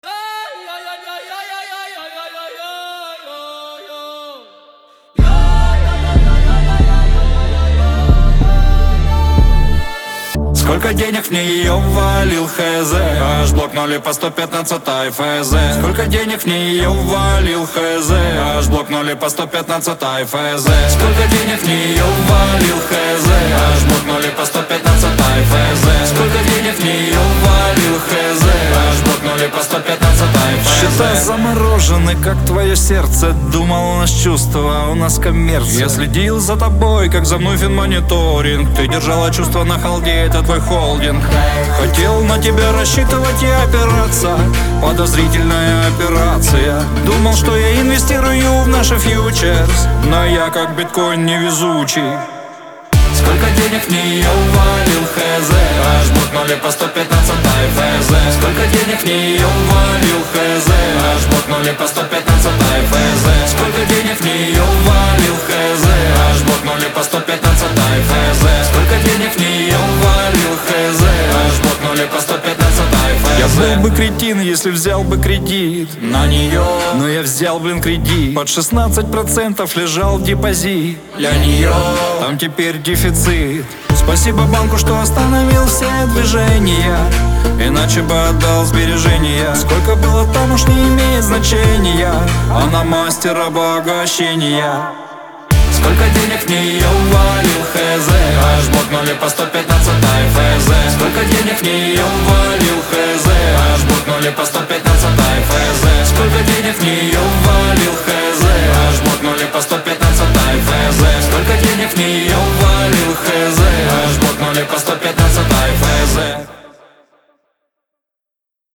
Веселая музыка
Шансон